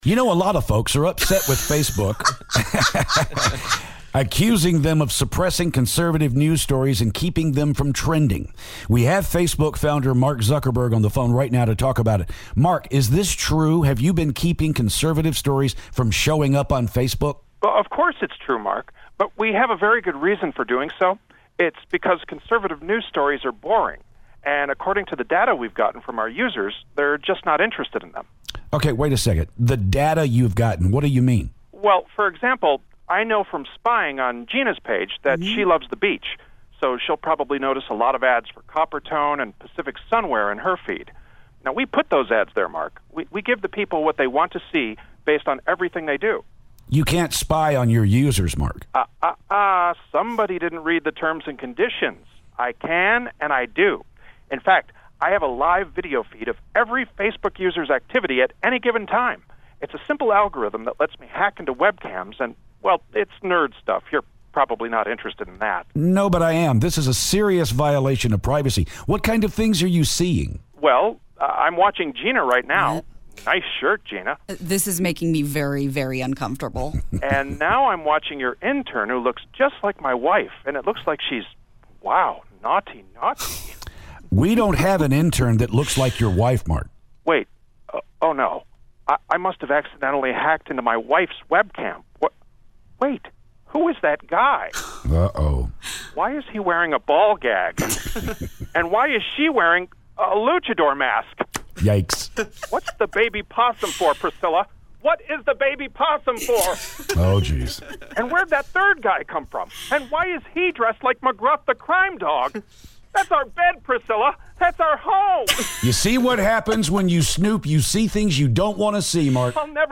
Mark Zuckerberg Phoner